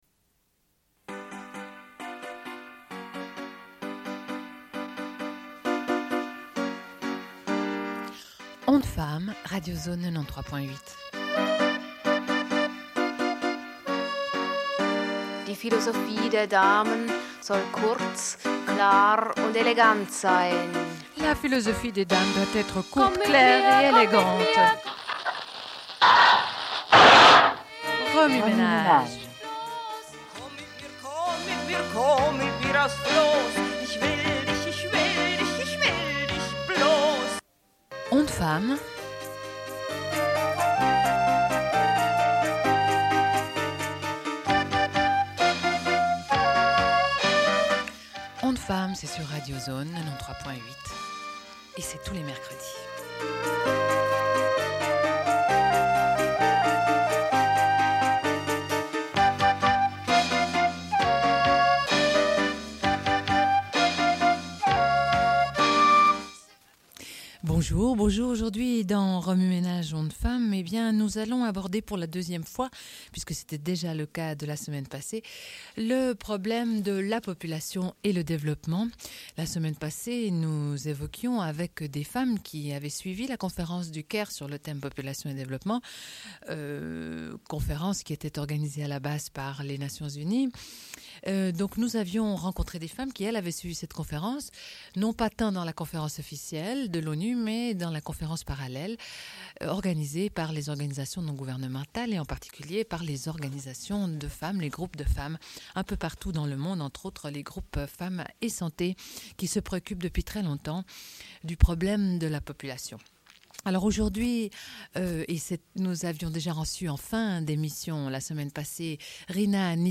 Une cassette audio, face A31:10